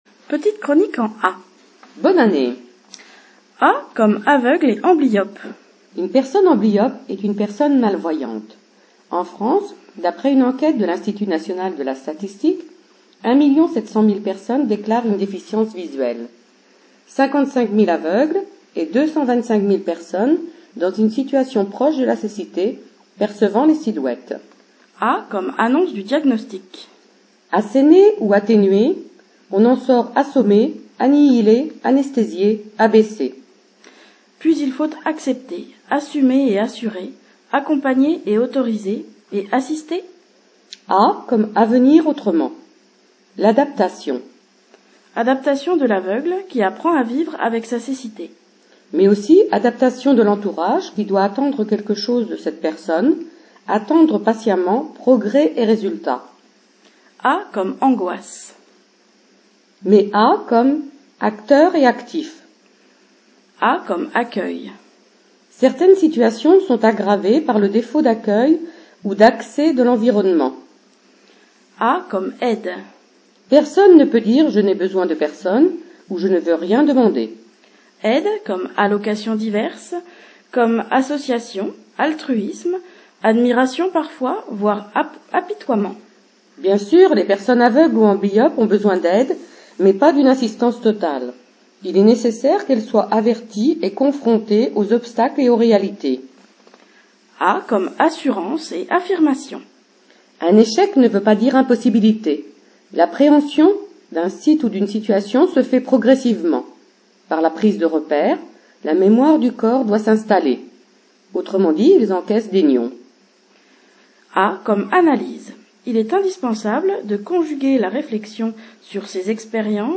Chronique diffusée sur les ondes de RCF Calvados le mercredi 7 janvier 2009